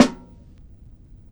HitSounds / Konga / ka.wav
ka.wav